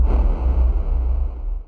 Techmino/media/effect/chiptune/enter.ogg at beff0c9d991e89c7ce3d02b5f99a879a052d4d3e
chiptune